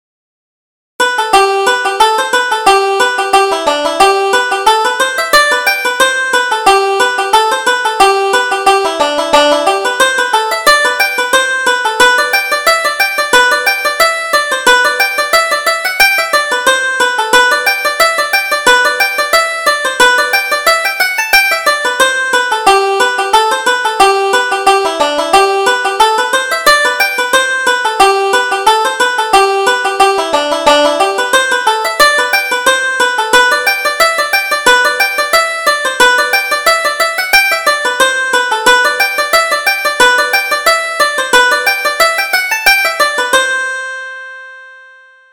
Reel: The Sporting Boys